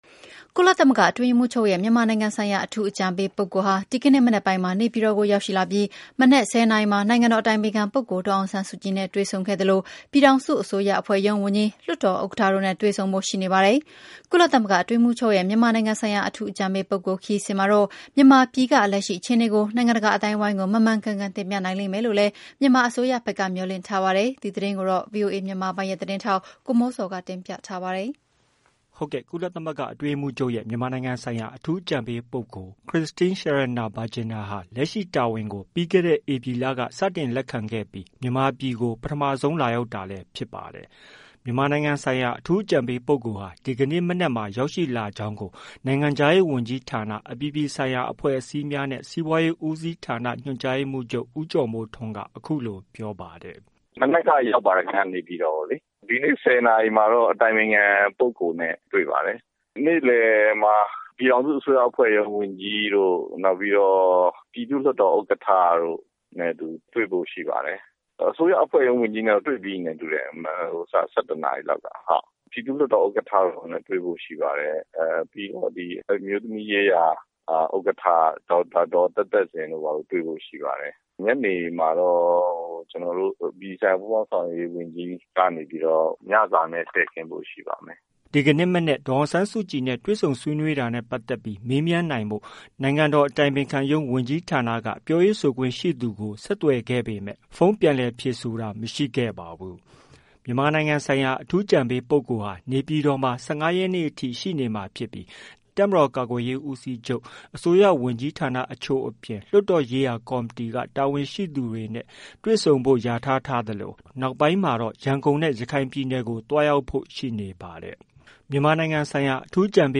မြန်မာနိုင်ငံဆိုင်ရာ အထူးအကြံပေးပုဂ္ဂိုလ်ဟာ ဒီကနေ့မနက်မှာ ရောက်ရှိလာကြောင်းကို နိုင်ငံခြားရေးဝန်ကြီးဌာန အပြည်ပြည်ဆိုင်ရာ အဖွဲ့အစည်းများနဲ့ စီးပွားရေးဦးစီးဌာန ညွှန်ကြားရေးမှူးချုပ် ဦးကျော်မိုးထွန်းက အခုလို ပြောပါတယ်။